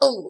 1 channel
herodamage3.mp3